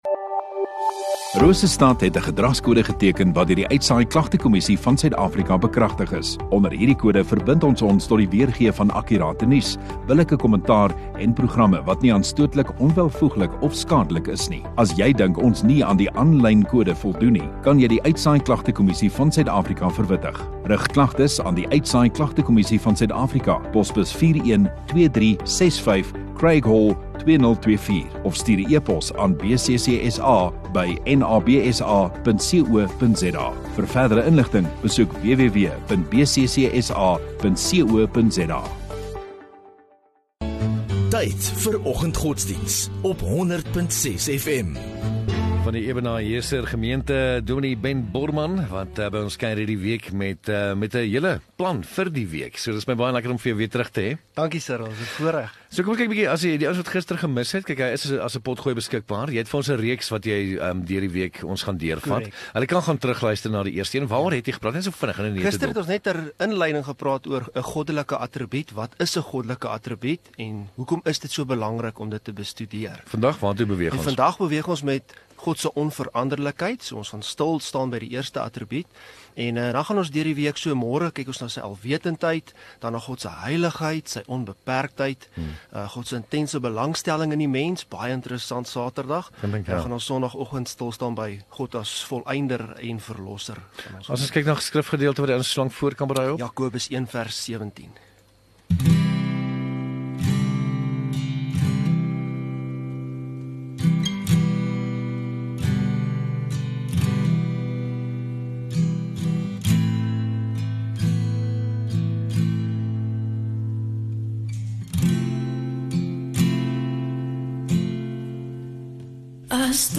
21 May Dinsdag Oggenddiens